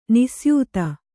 ♪ nisyūta